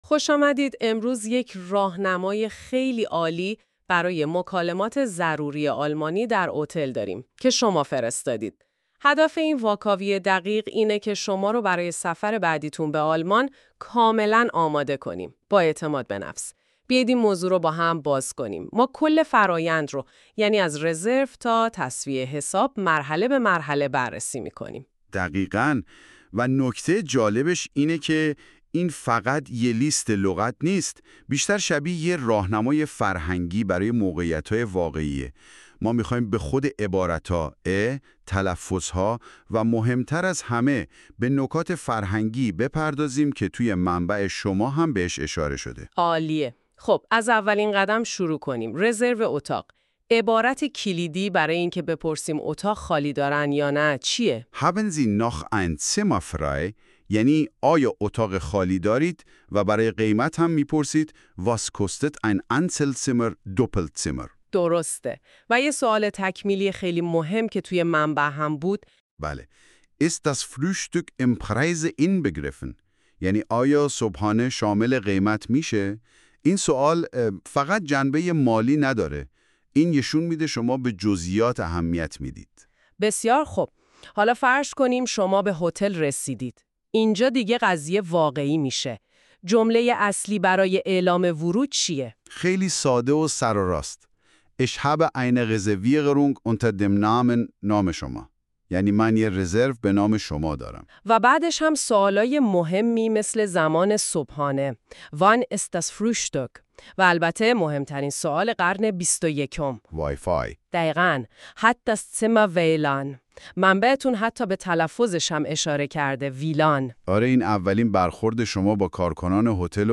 german-conversation-at-the-hotel.mp3